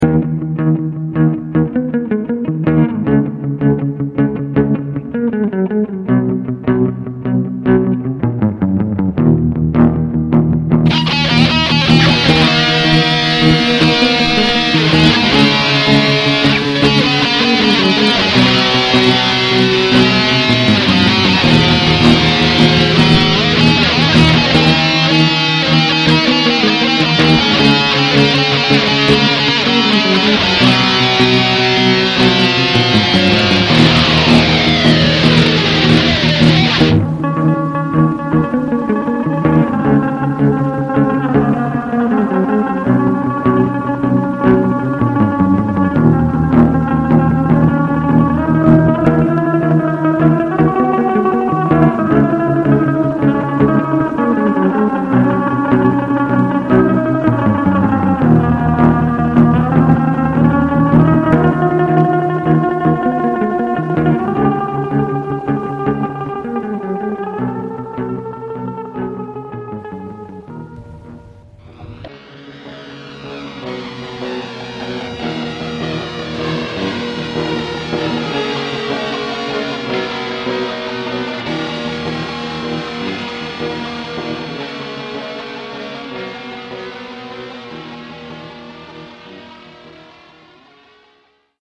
• Категория: Тест акустики